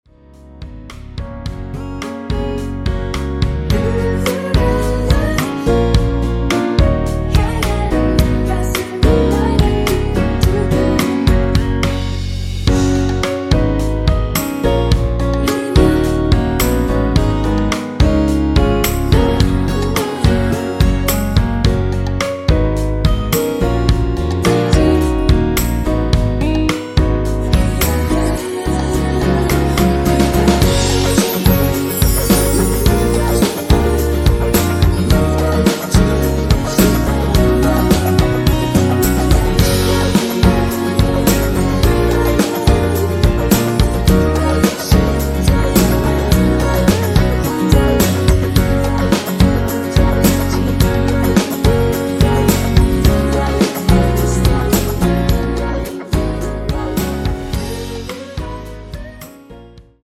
원키에서(-1)내린 코러스 포함된 MR입니다.(미리듣기 확인)
Gb
앞부분30초, 뒷부분30초씩 편집해서 올려 드리고 있습니다.
중간에 음이 끈어지고 다시 나오는 이유는